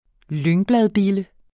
Udtale [ ˈløŋˌblaðˌbilə ]